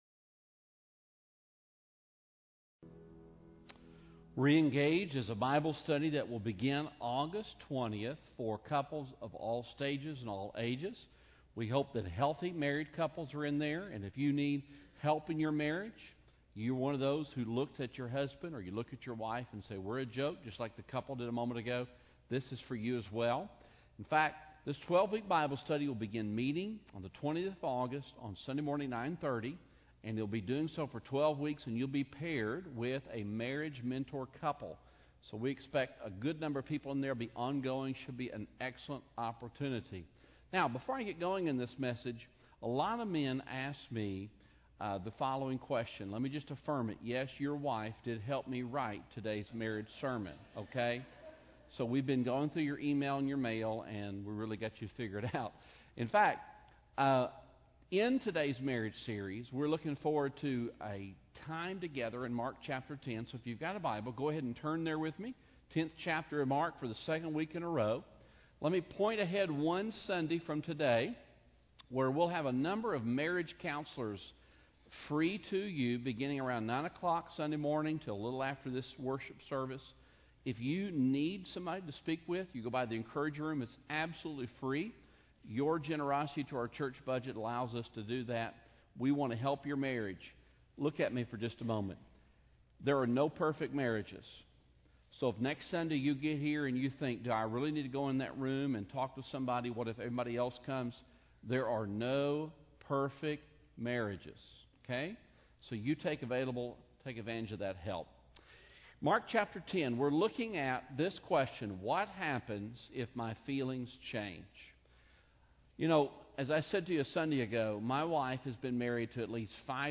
Marriage Sermon Series — Cross Church - NRH Campus